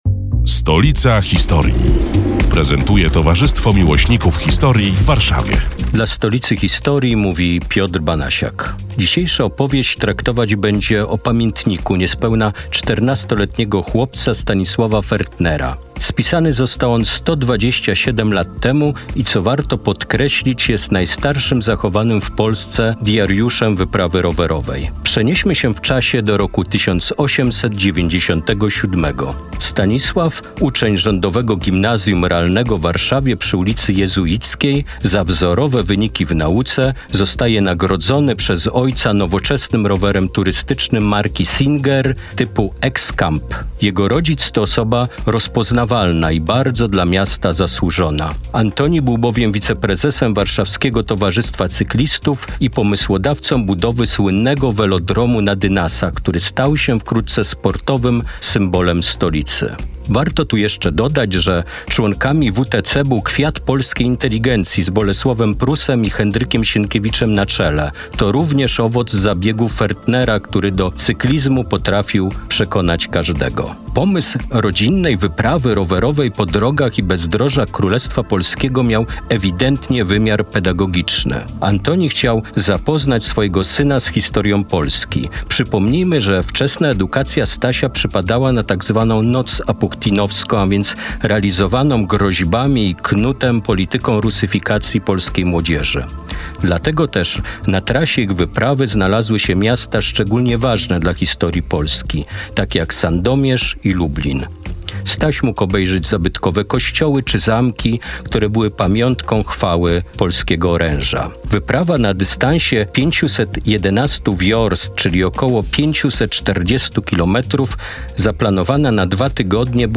Ostatni przed wakacjami felieton pod wspólną nazwą: Stolica historii. Przedstawiają członkowie Towarzystwa Miłośników Historii w Warszawie, które są już od ponad półtora roku emitowane w każdą sobotę, w nieco skróconej wersji, w Radiu Kolor.